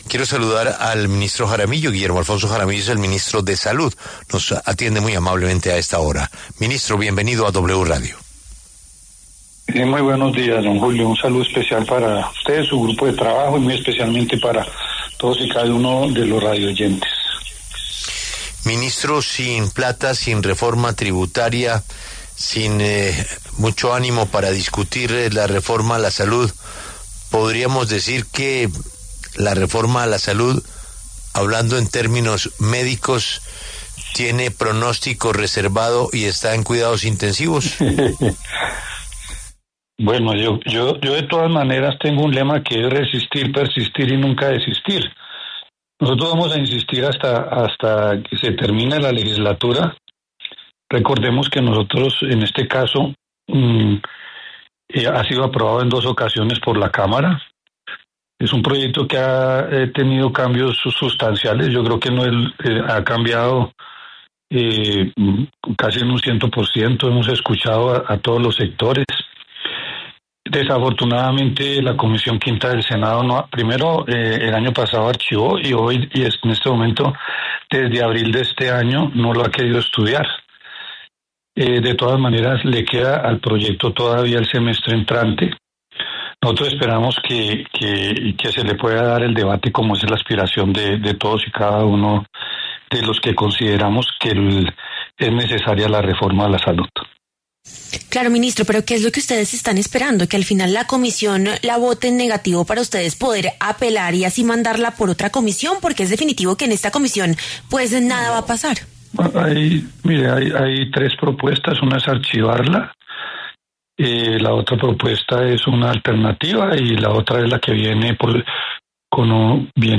El ministro de Salud, Guillermo Alfonso Jaramillo, habló en la W sobre la discusión de la reforma en la salud en el Congreso.